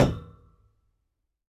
tbd-station-14/Resources/Audio/Effects/Footsteps/hull2.ogg